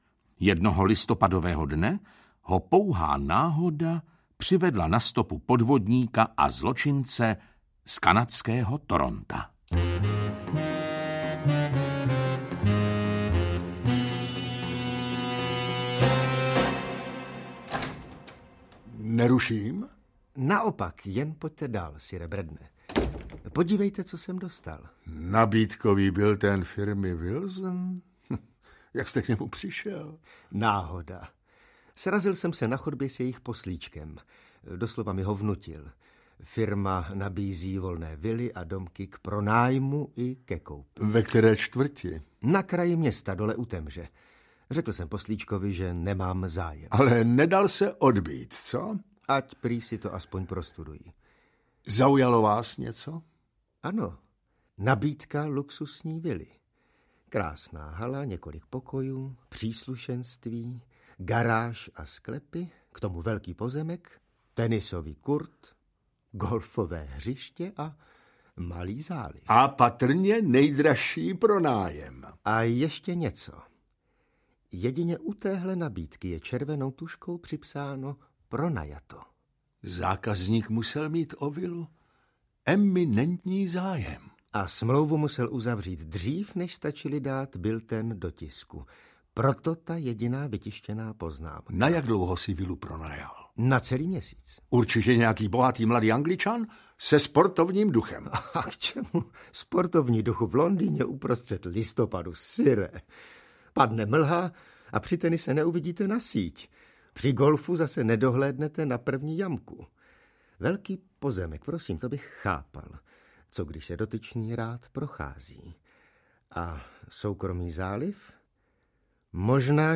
Kriminální příběhy Johna G. Reedera audiokniha
Ukázka z knihy